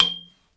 ding hit metal ring ting tone sound effect free sound royalty free Sound Effects